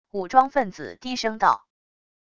武装分子低声道wav音频